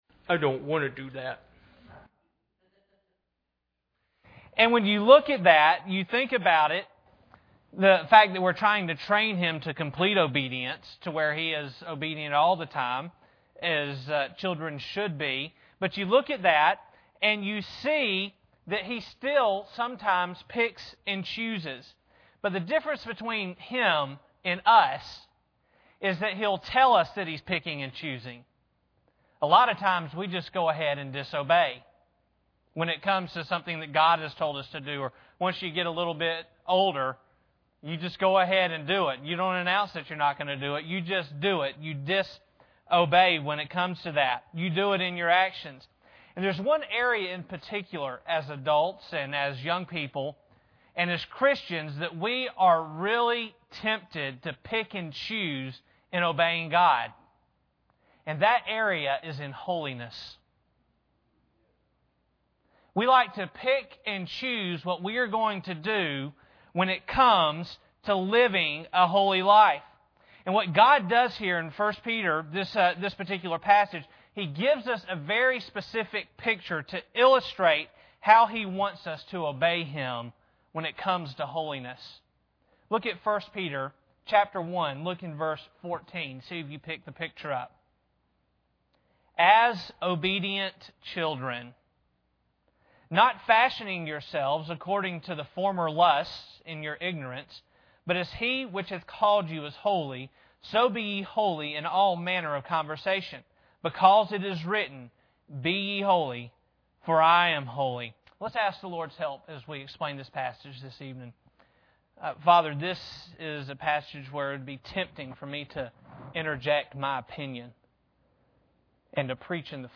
1 Peter 1:14-15 Service Type: Sunday Evening Bible Text